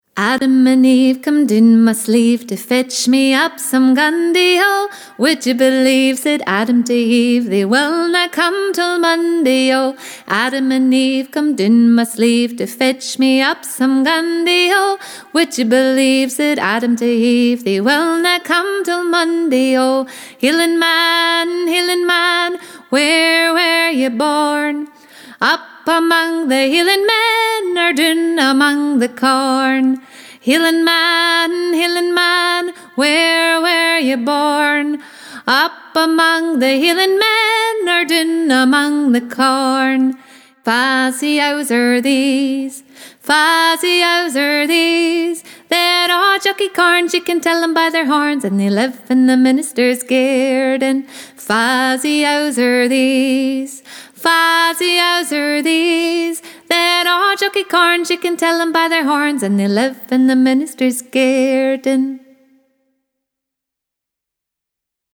Scottish Music Download Adam & Eve/Hielan’ Man/Fa’s Yowes?